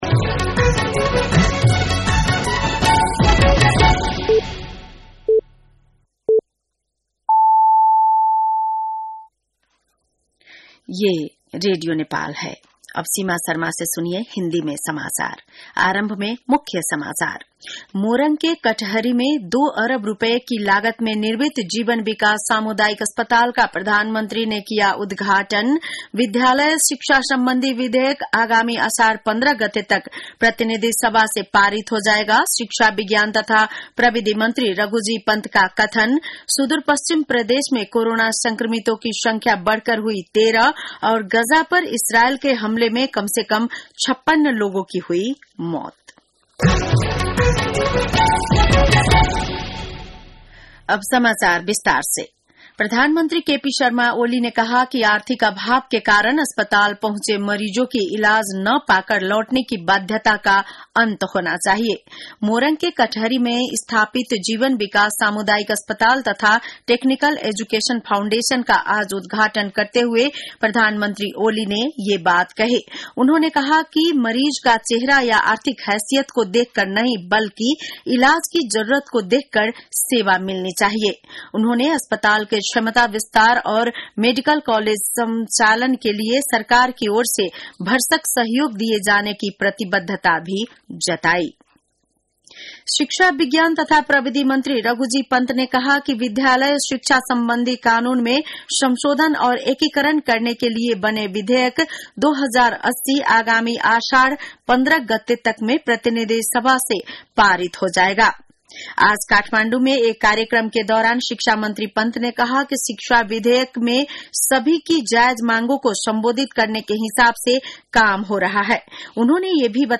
बेलुकी १० बजेको हिन्दी समाचार : २५ जेठ , २०८२